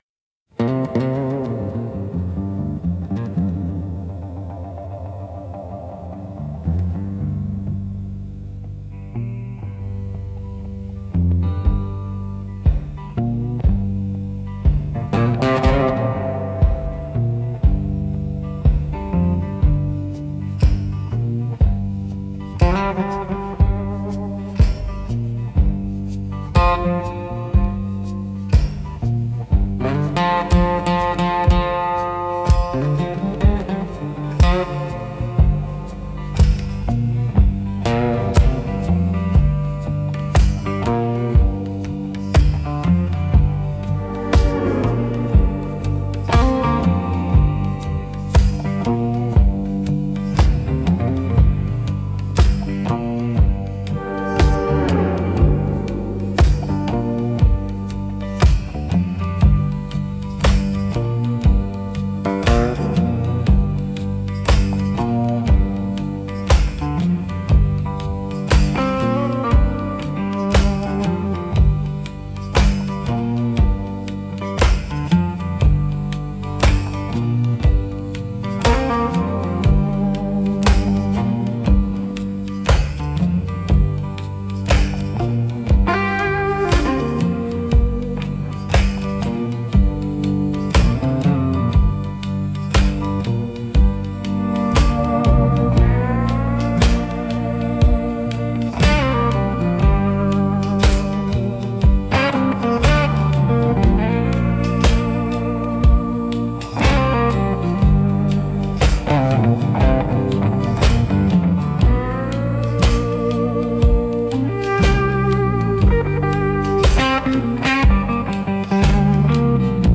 Instrumentals - Slow Drag Through the Hollow